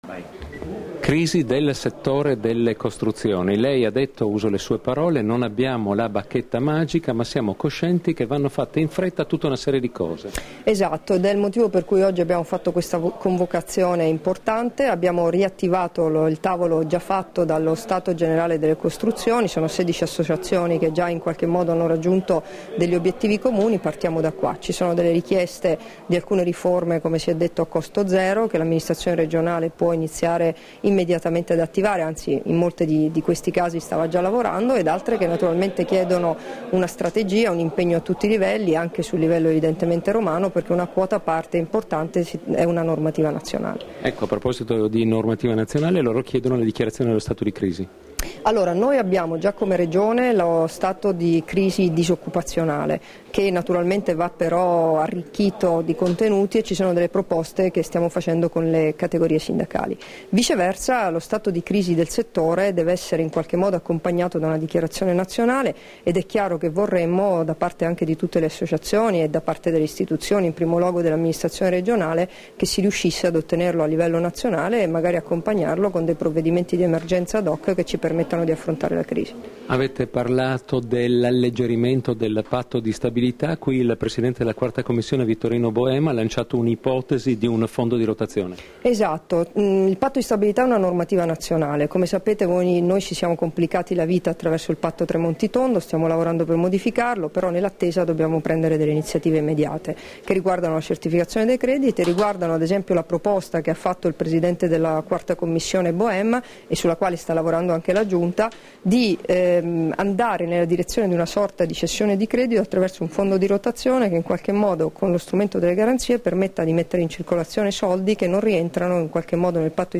Ascolta le dichiarazioni di Debora Serracchiani (Formato MP3) rilasciate a margine degli Stati generali delle Costruzioni, a Udine il 26 giugno 2013 [3578KB]